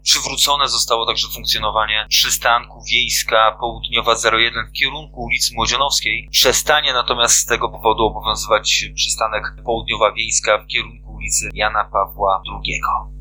Moc MiastaWiadomości Radom